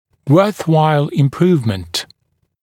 [ˌwɜːθ’waɪl ɪm’pruːvmənt][ˌуё:с’уайл им’пру:вмэнт]улучшение, стоящее затраченных усилий